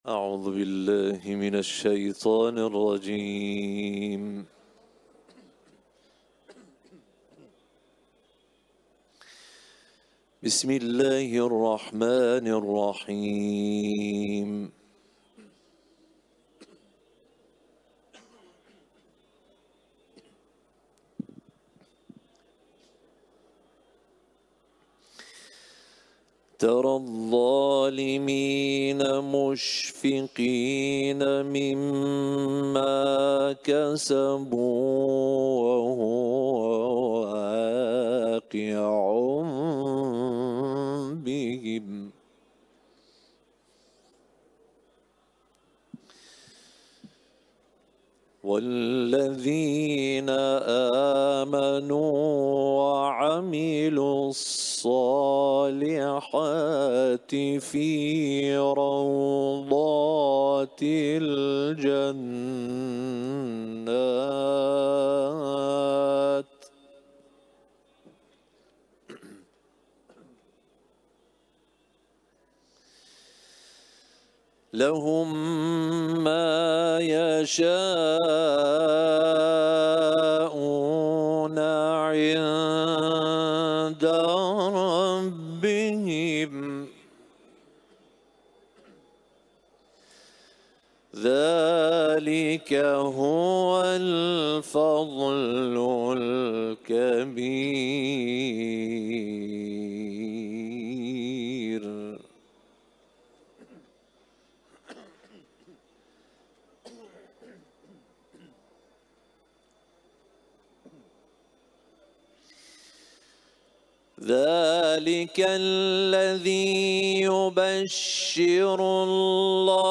Etiketler: İranlı kâri ، Kuran tilaveti ، Şûrâ suresi